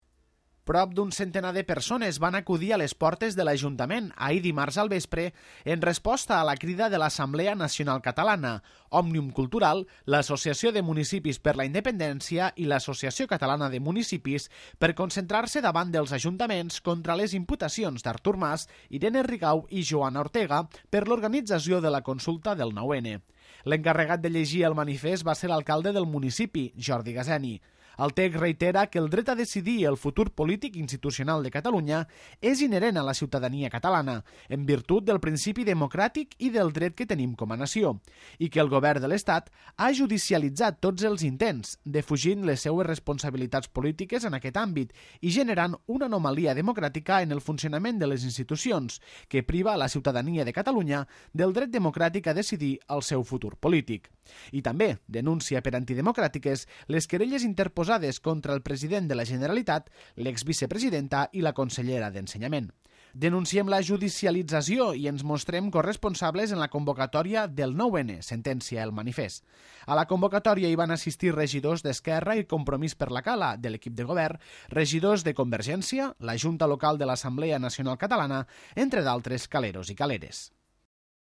Prop de 100 persones es van concentrar ahir al vespre a l'Ajuntament contra les imputacions d'Artur Mas, Irene Rigau i Joana Ortega per l'organització de la consulta del 9-N.
L'encarregat de llegir el manifest va ser l'alcalde del municipi, Jordi Gaseni.